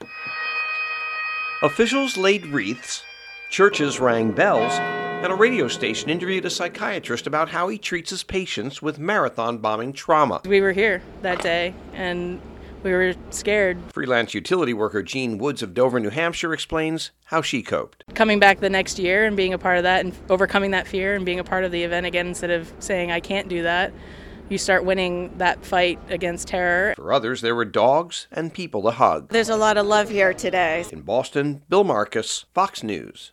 IS AT THE BOSTON MARATHON FINISH LINE: